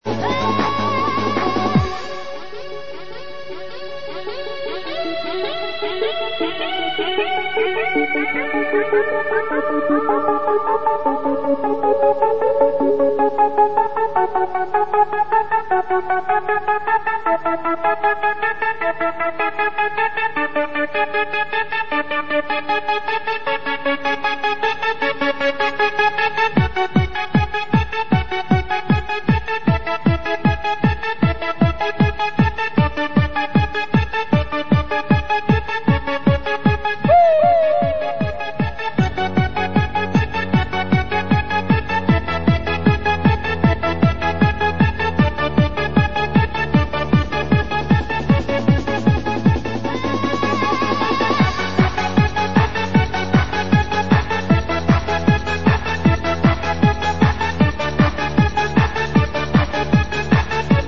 Hi guys, another great old trance tune.